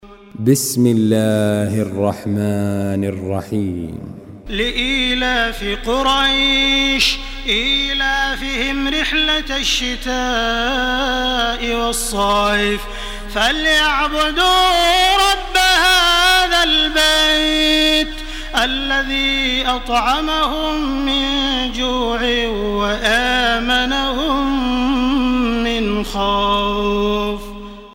Surah Quraish MP3 by Makkah Taraweeh 1429 in Hafs An Asim narration.
Murattal